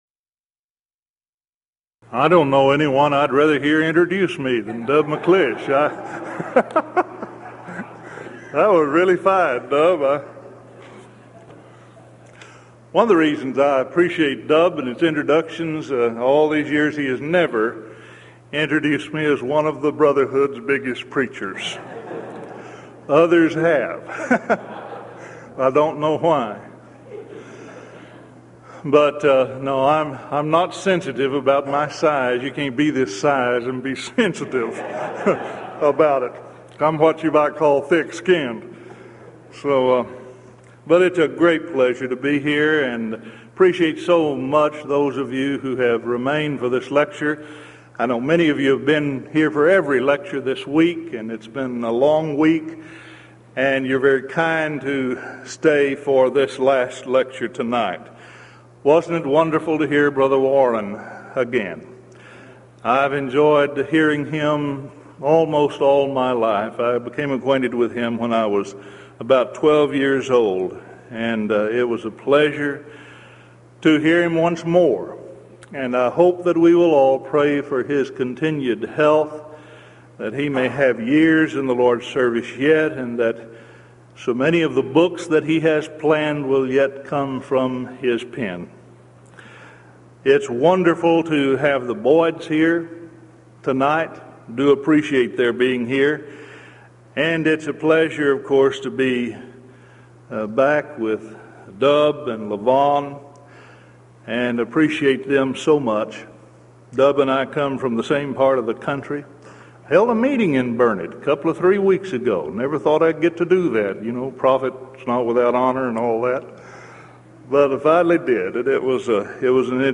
Event: 1993 Denton Lectures
lecture